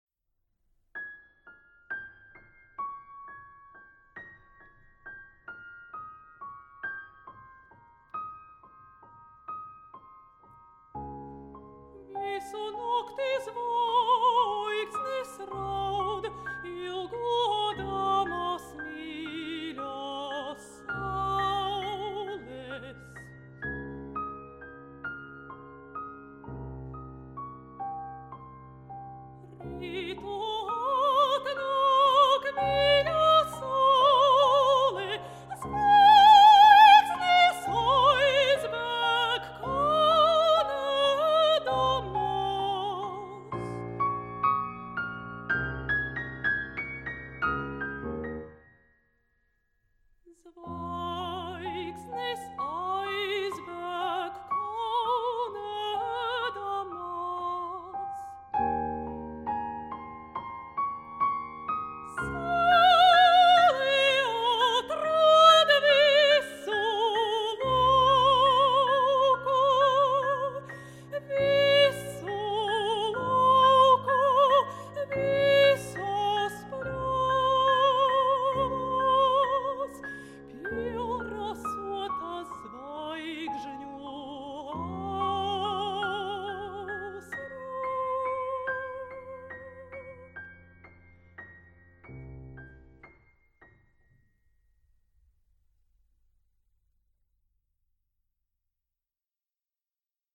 Žanrs: Vokālā kamermūzika
Instrumentācija: balsij, klavierēm